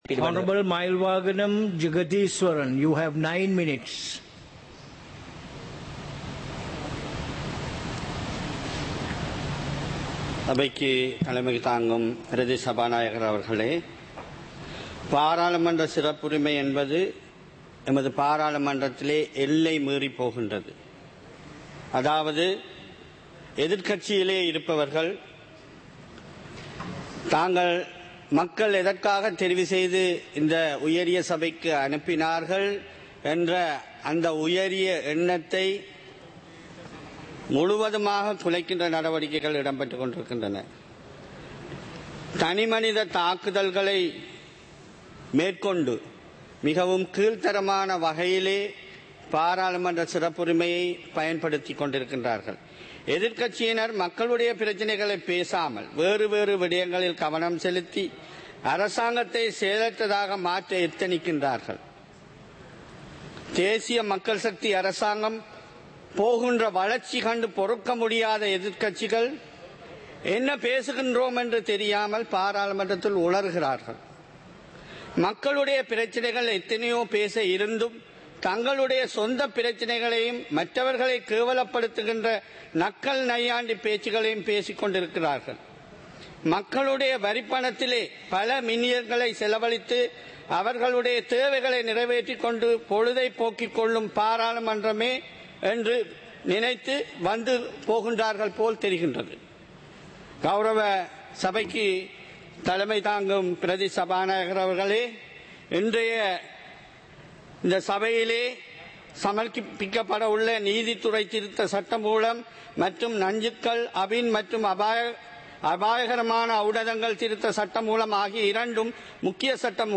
சபை நடவடிக்கைமுறை (2026-02-19)